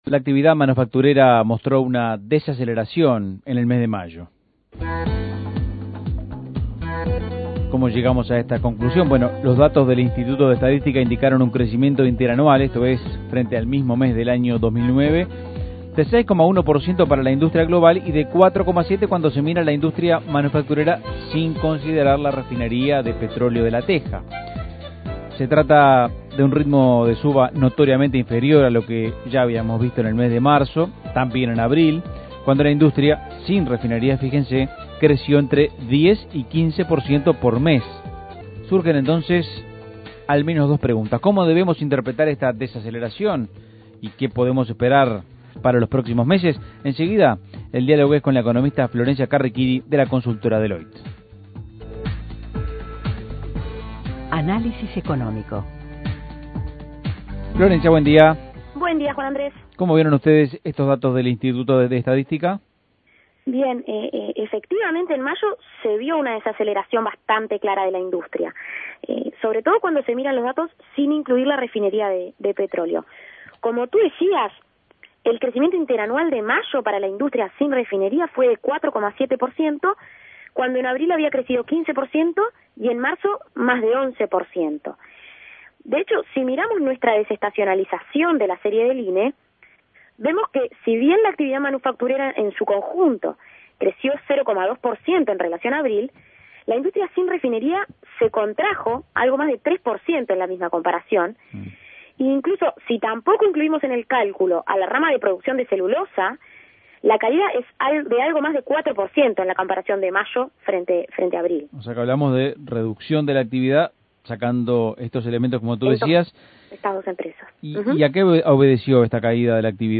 Análisis Económico La industria manufacturera mostró una desaceleración apreciable en mayo.